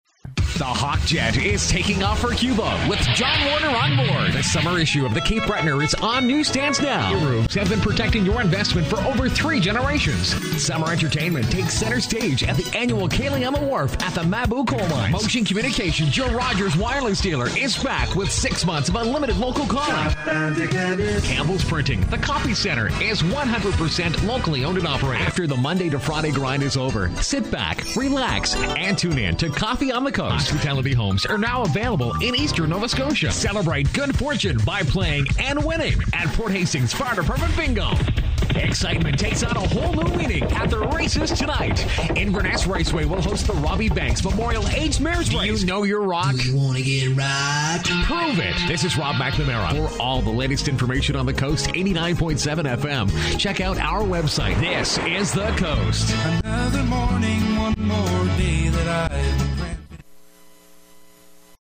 Authentic, Uptempo, Hard-Sell